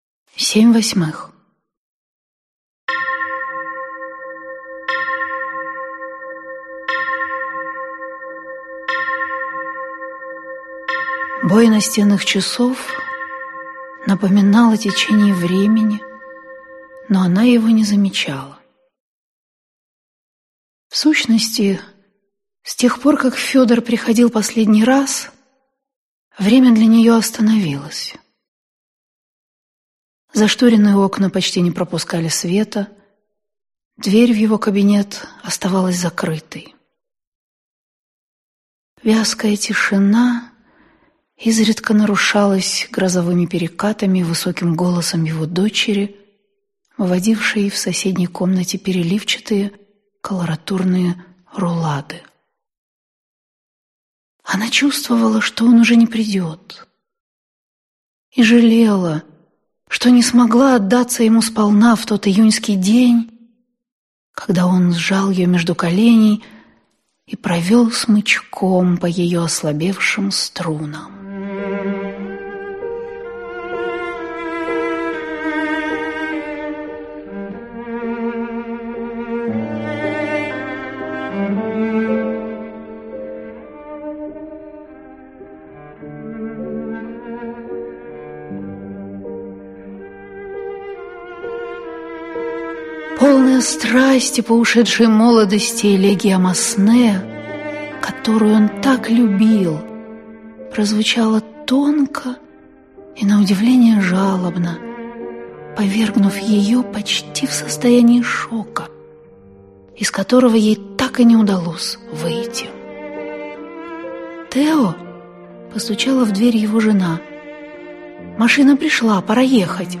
Аудиокнига Семь восьмых | Библиотека аудиокниг
Прослушать и бесплатно скачать фрагмент аудиокниги